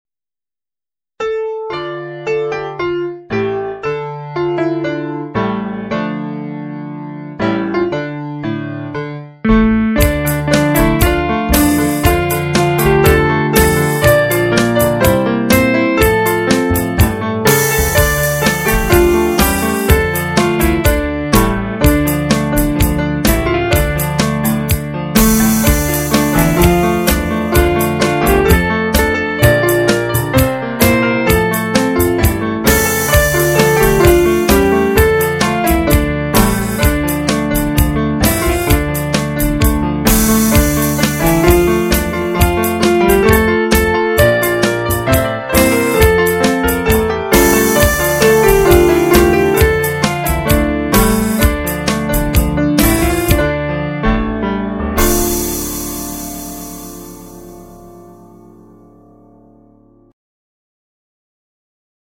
- Instrumental
(Popular d'Israel)